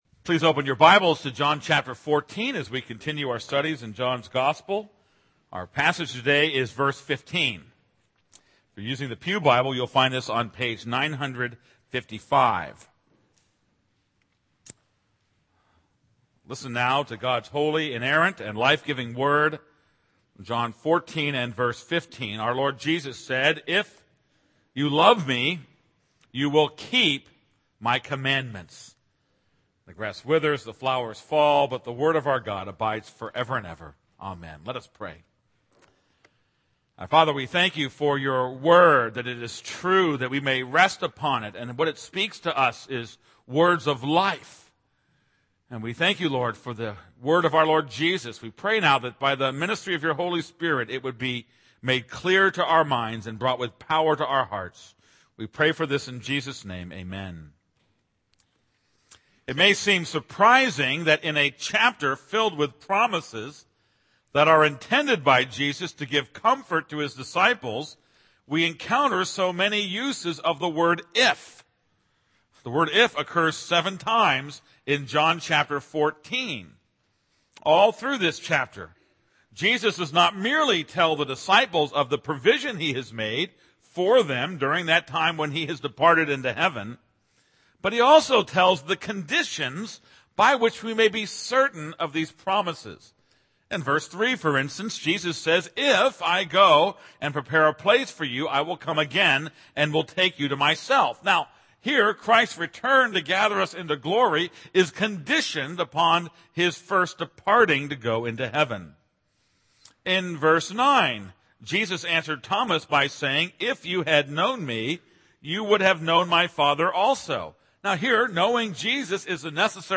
This is a sermon on John 14:15.